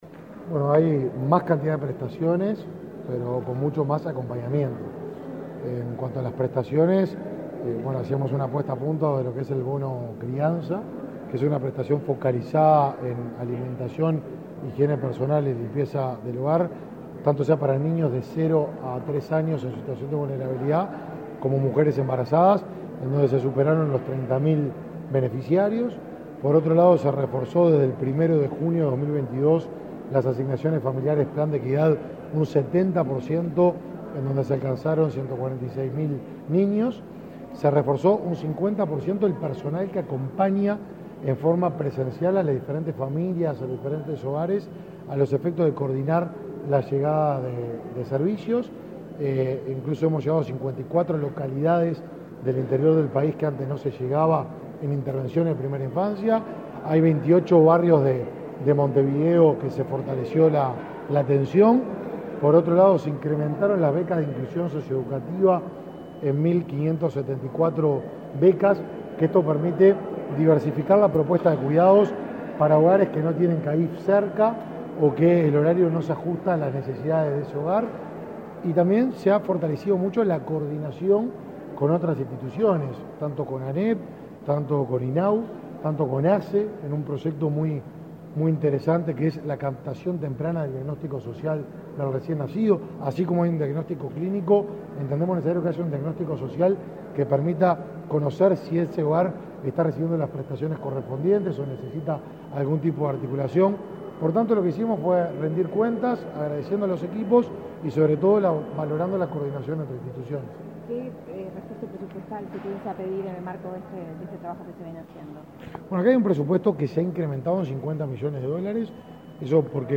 Declaraciones a la prensa del ministro del Mides, Martín Lema
Declaraciones a la prensa del ministro del Mides, Martín Lema 03/05/2023 Compartir Facebook X Copiar enlace WhatsApp LinkedIn El Ministerio de Desarrollo Social (Mides), a través de la Dirección Nacional de Desarrollo Social, realizó el lanzamiento del Mes de la Primera Infancia. Tras el evento, el ministro Lema realizó declaraciones a la prensa.